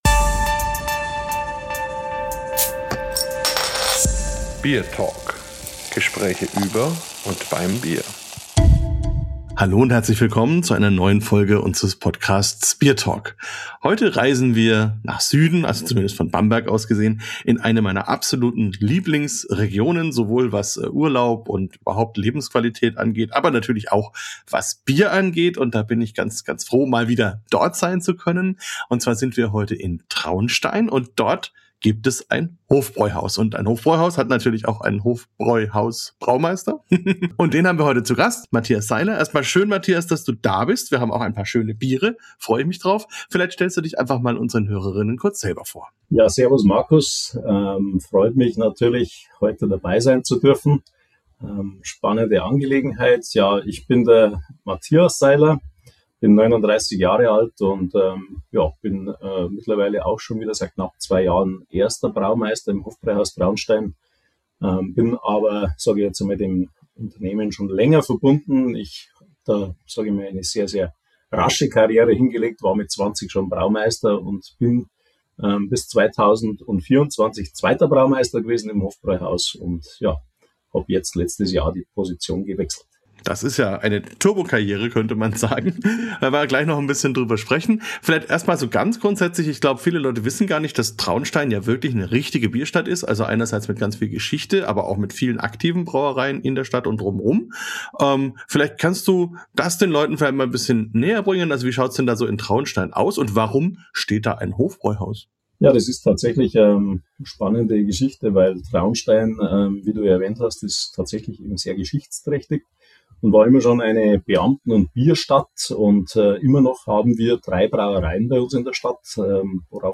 BierTalk 157 – Interview
Dazu gibt’s eine Verkostung mit fünf Bieren: vom alkoholfreien Zwickl über Helles und Pils bis zu Dunklem Weißbier und Dunklem Doppelbock. Eine Folge über Braukultur, Erfahrung – und darüber, wie Tradition lebendig bleibt, wenn man sie wirklich versteht.